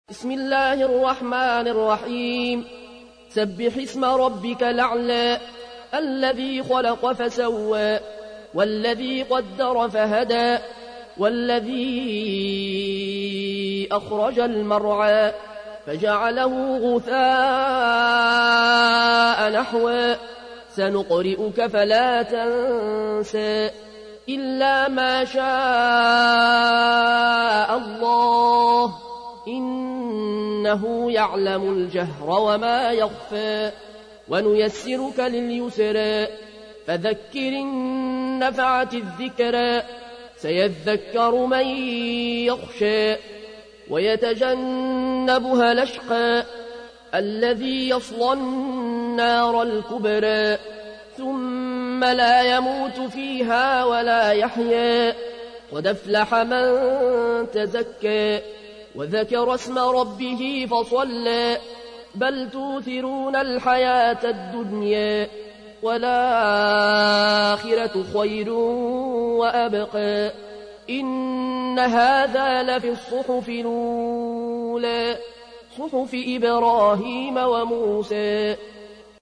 تحميل : 87. سورة الأعلى / القارئ العيون الكوشي / القرآن الكريم / موقع يا حسين